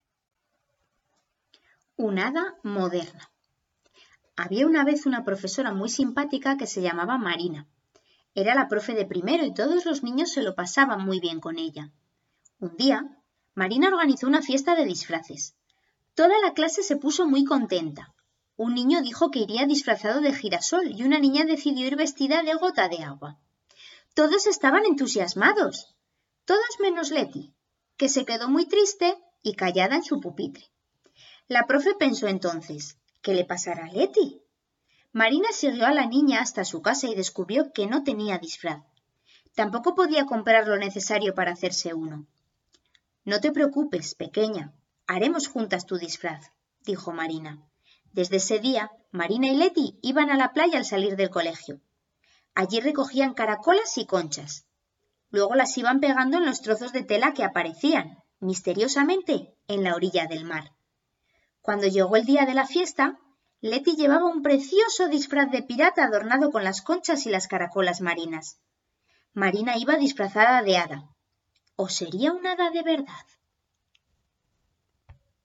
Lectura_pag_28.mp3